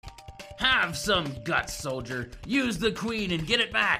Sarge Sound effect